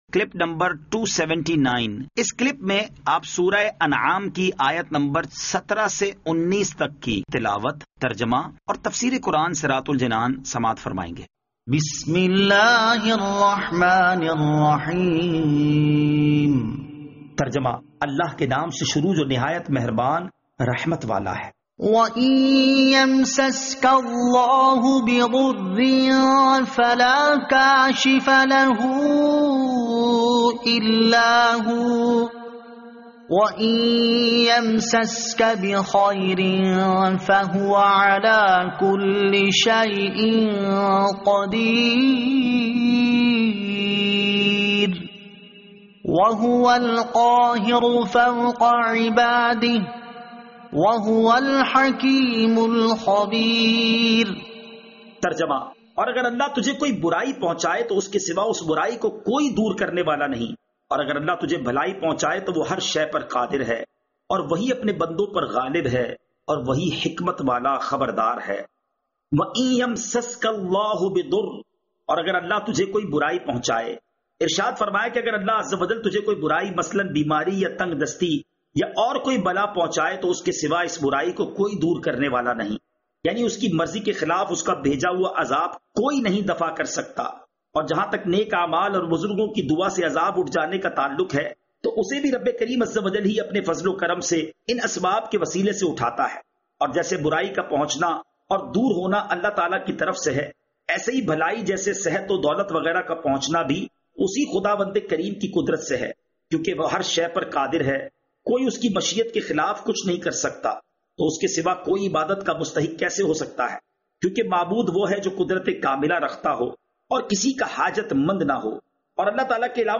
Surah Al-Anaam Ayat 17 To 19 Tilawat , Tarjama , Tafseer